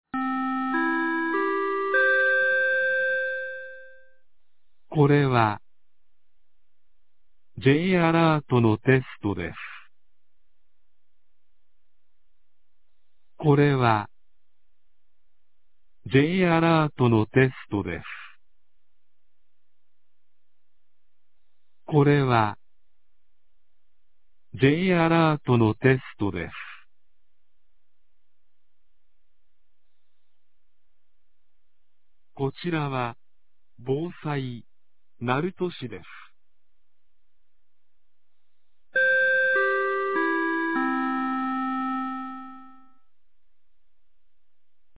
2025年02月12日 11時00分に、鳴門市より全地区へ放送がありました。